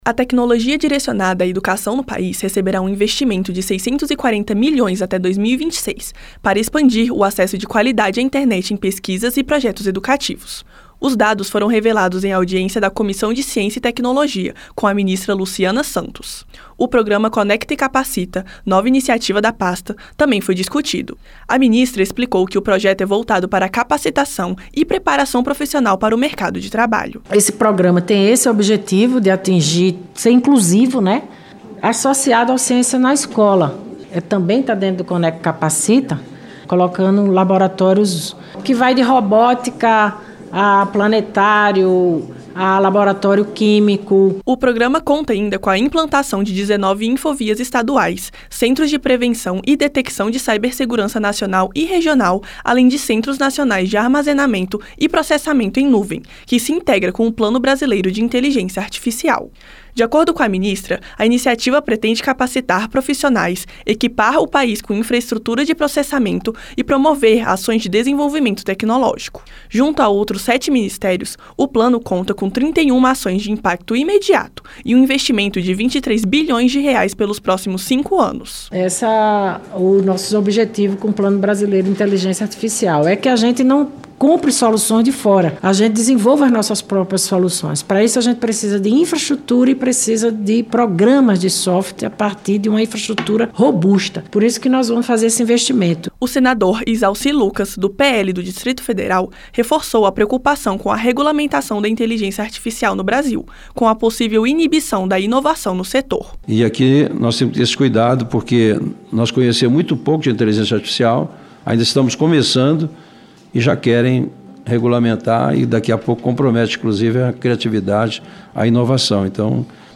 Audiência na CCT ouve ministra da Ciência e Tecnologia sobre internet e IA